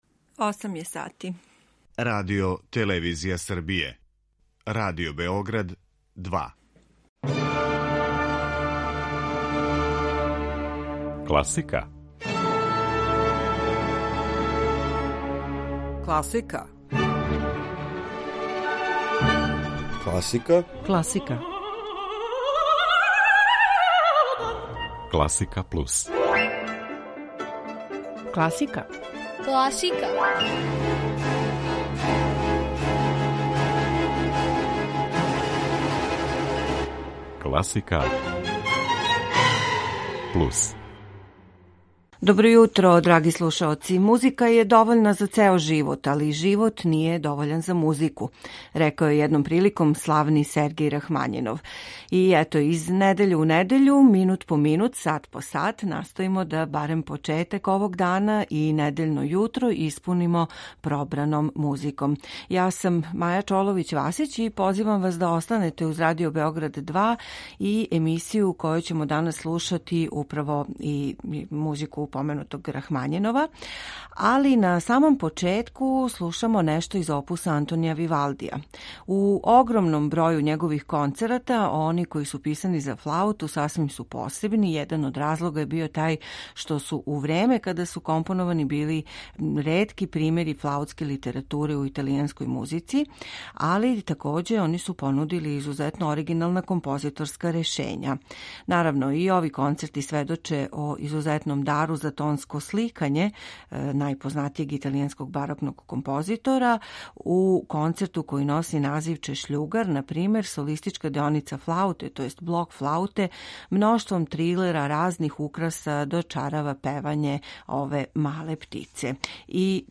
барокног концерта за флауту
виртуозне сонате за виолину
композиције писане за гамелан оркестар